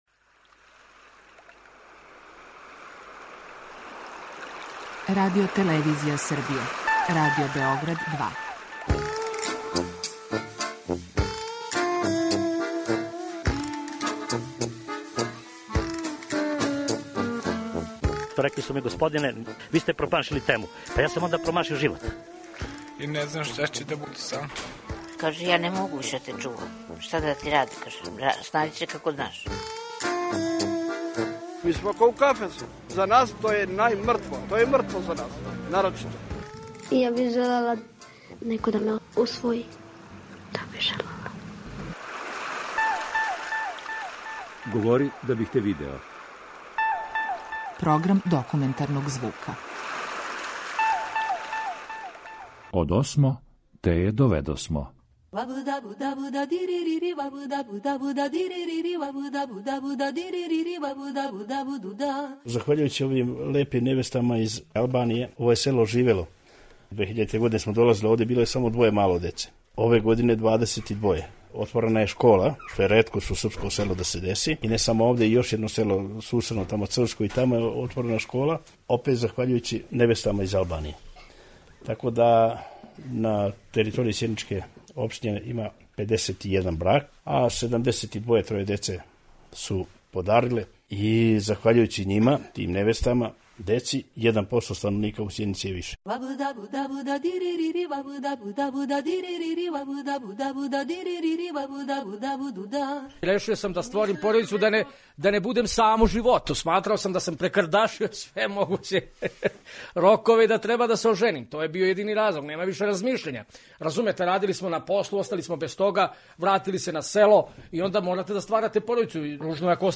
Документарни програм
У емисији говоре житељи села Вишњице и Буђево са Пештерске висоравни, који су се оженили Албанкама из околине Скадра.